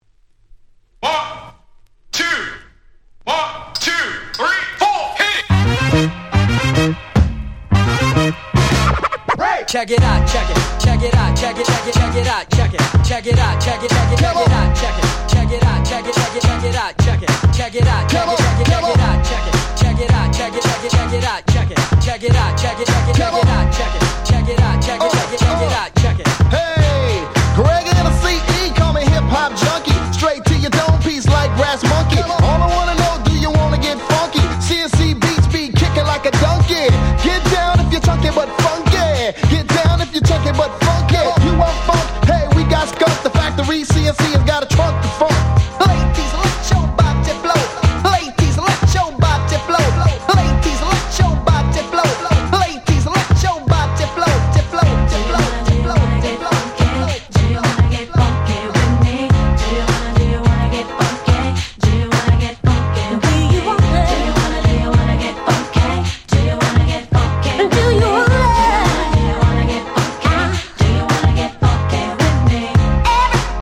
90's R&B デリシャスミックス 勝手にリミックス ミックス物